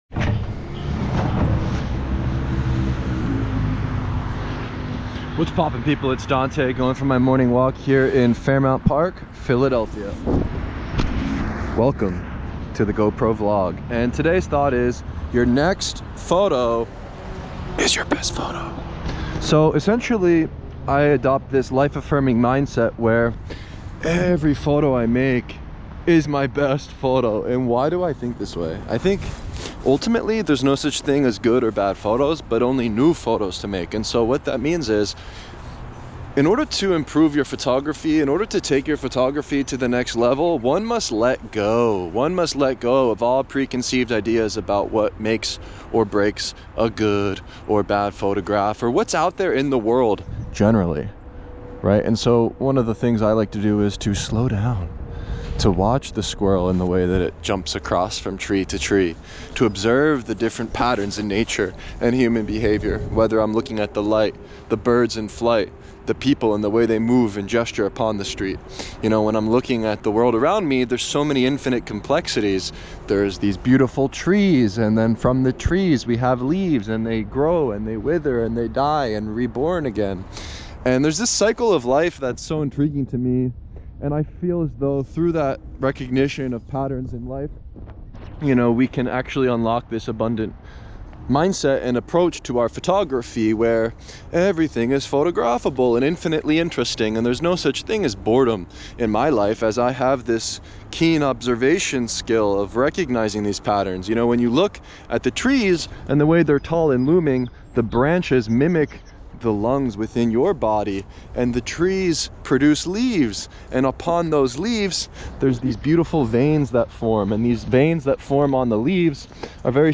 Welcome to the GoPro vlog.